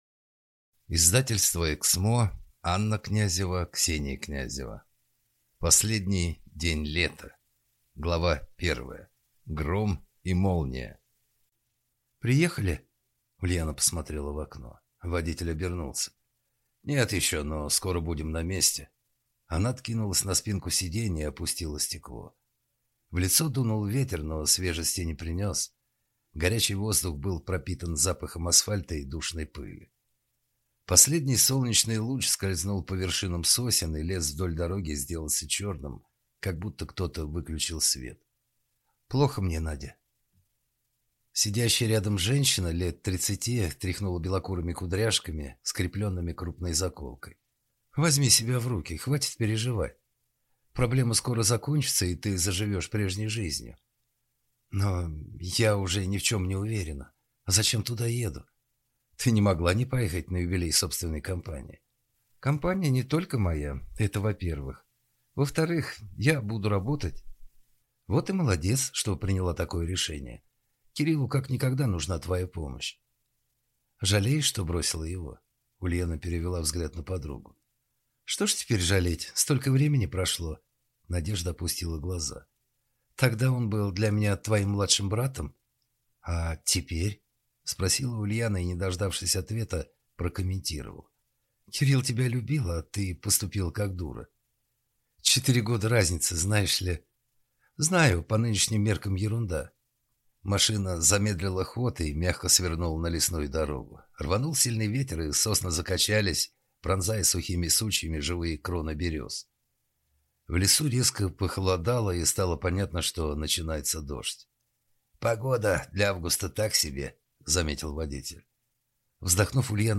Аудиокнига Последний день лета | Библиотека аудиокниг